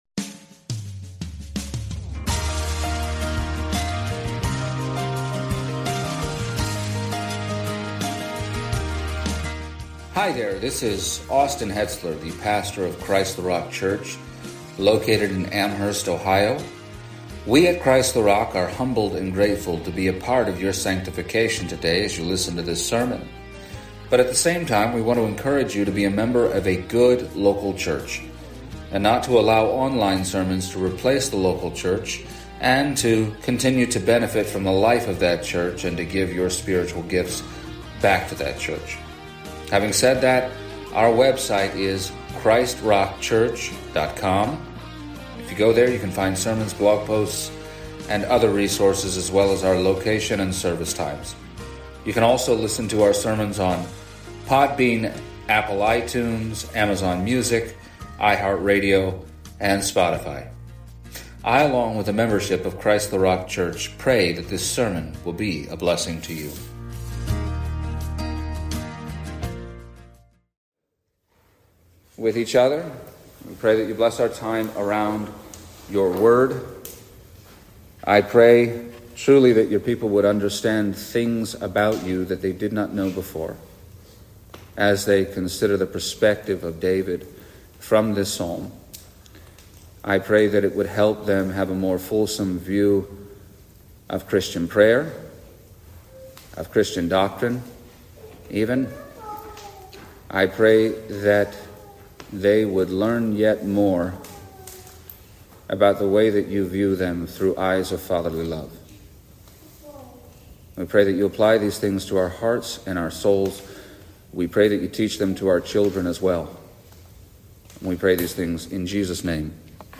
The first of two messages given at the 2025 CtRC family camp